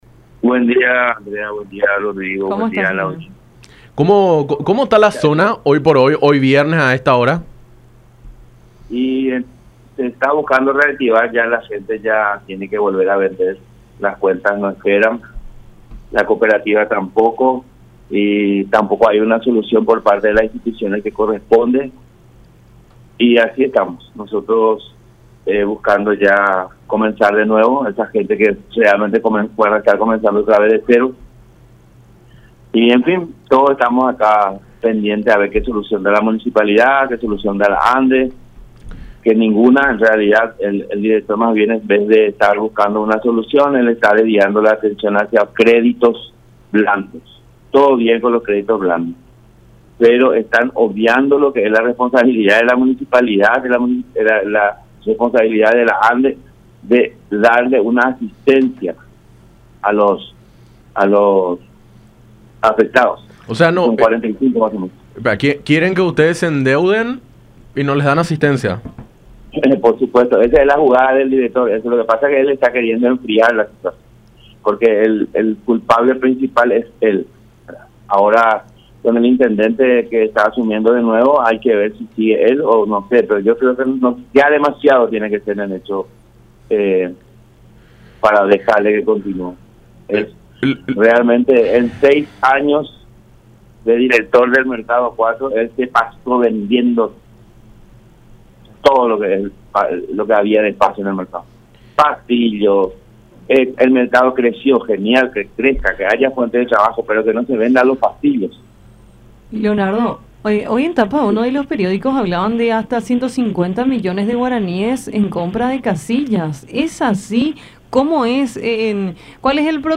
en conversación con Enfoque 800 a través de La Unión